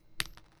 uiTap.wav